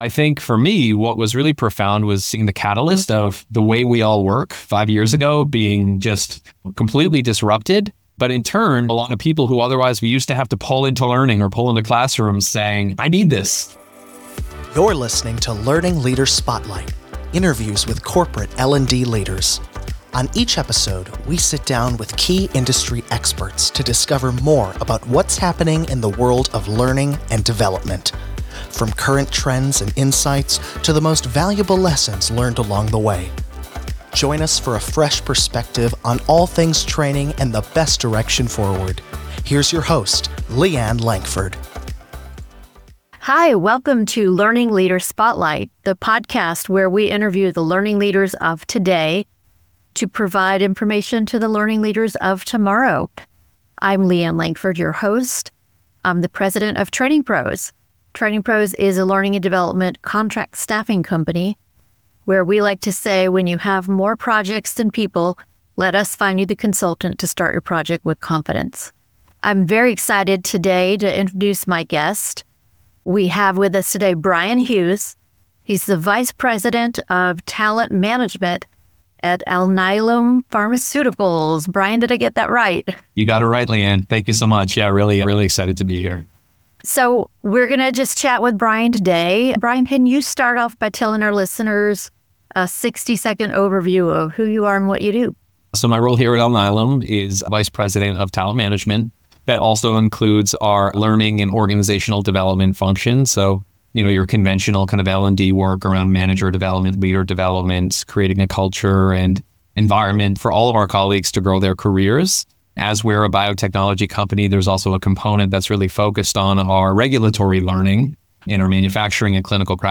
Each week, we’ll dive into the details of what it takes to thrive in learning and development, as guests from different corporate contexts and sectors share their expertise and experience.